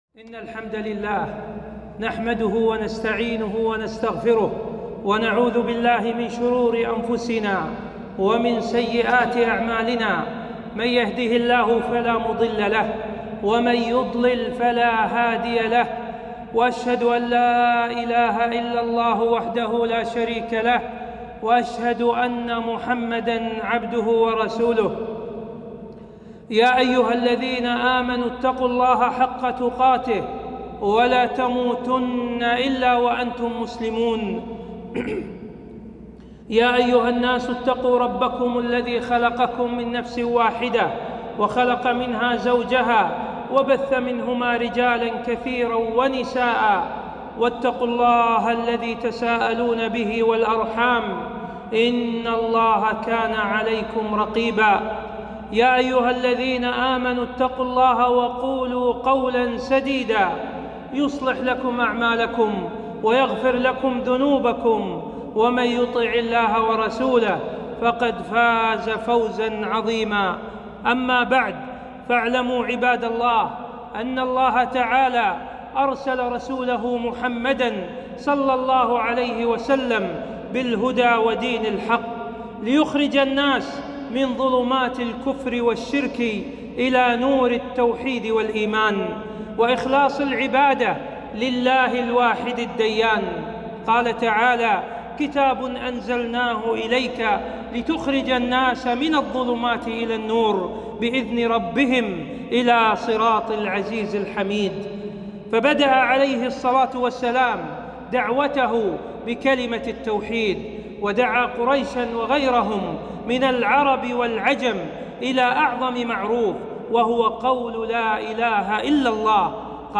خطبة - وجوب التوحيد وفضله